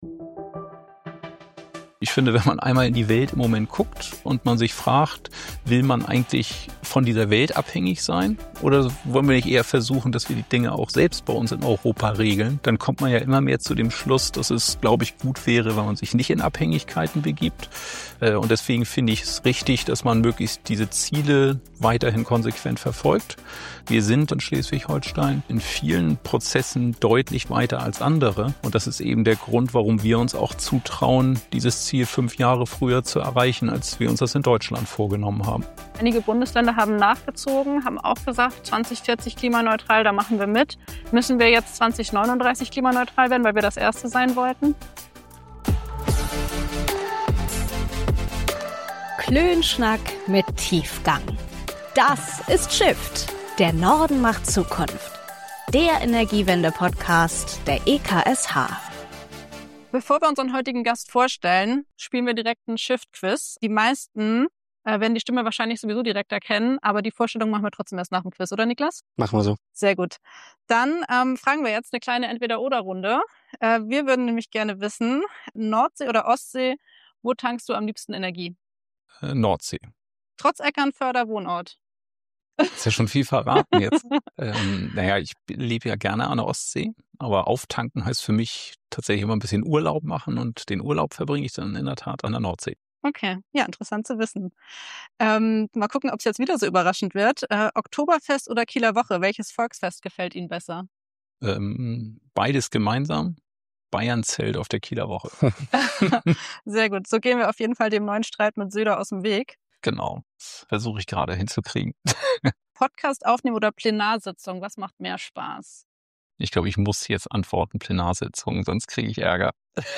Heute nimmt kein geringerer als Schleswig-Holsteins Ministerpräsident Daniel Günther an unserem Kaffeetisch Platz.